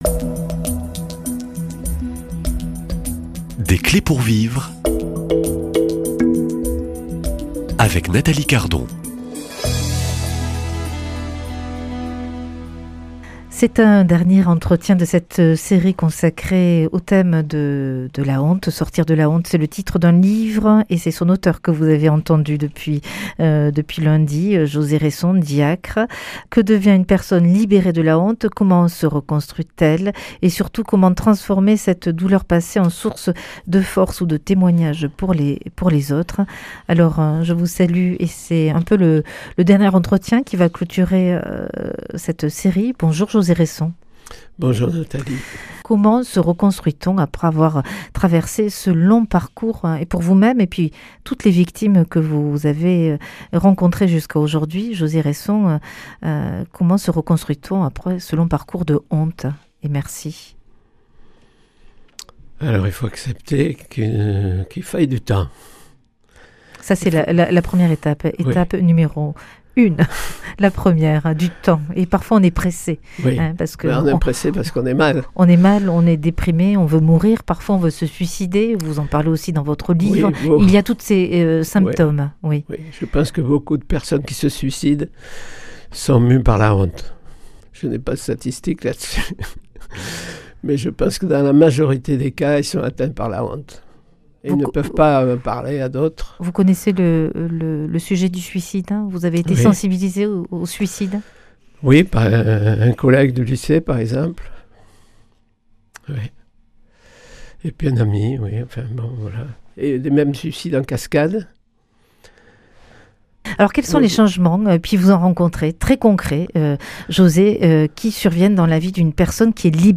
Ce dernier entretien ouvre à l’espérance : que devient une personne libérée de la honte ?